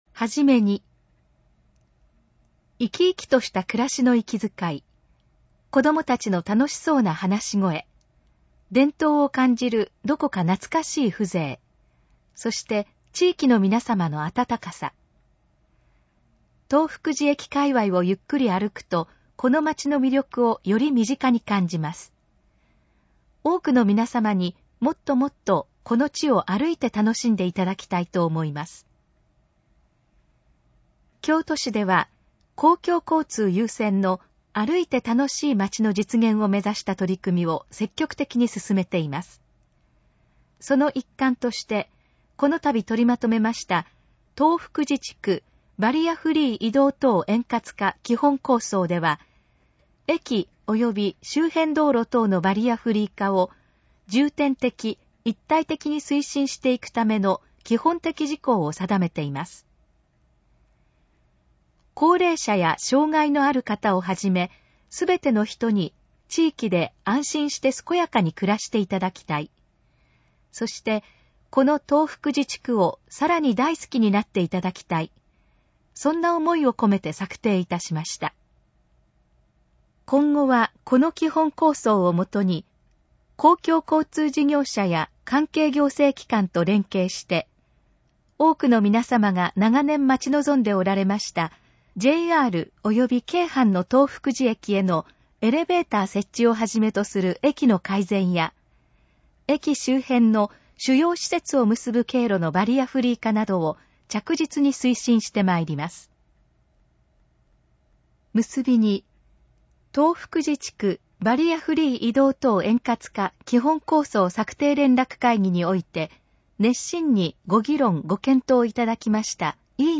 このページの要約を音声で読み上げます。
ナレーション再生 約540KB